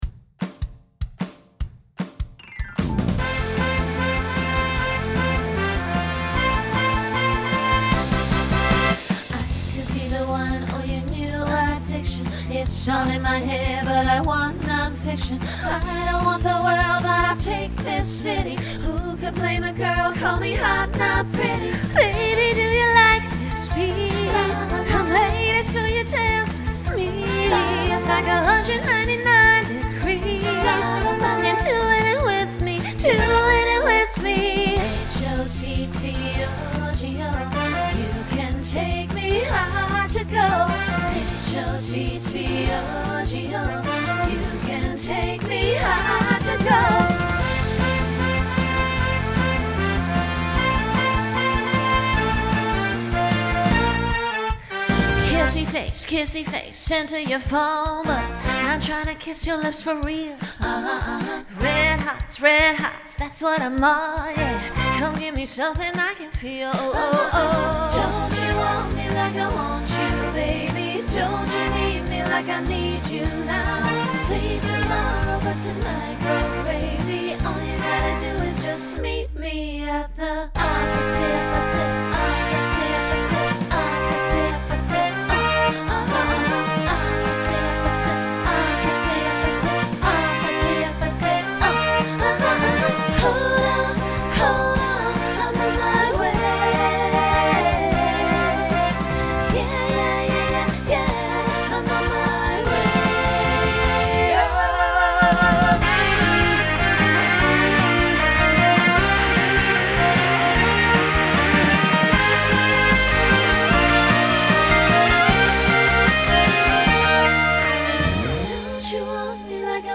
Genre Pop/Dance
Voicing SSA